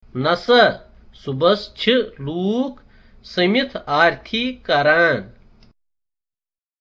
This concerns a conversation on visiting   (the Shankracharya Temple), situated on the top of a mountain to the south of Dal Lake.